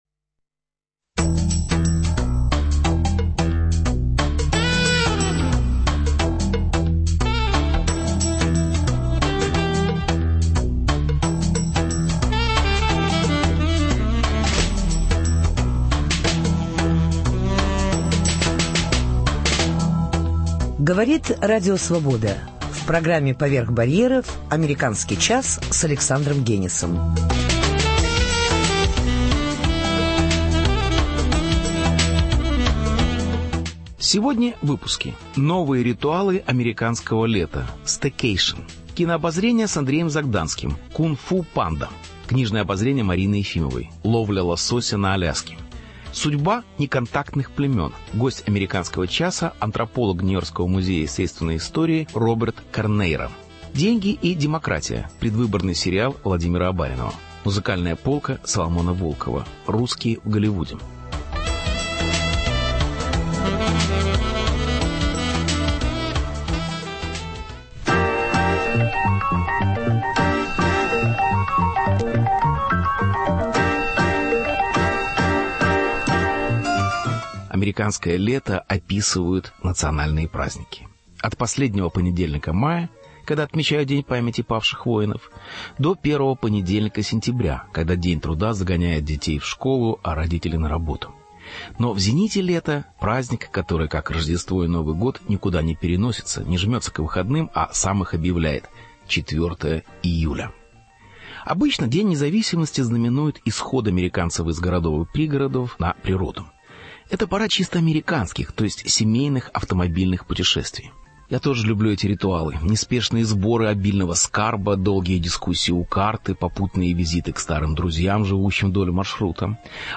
Интервью. Судьбы неконтактных племен.
Репортаж.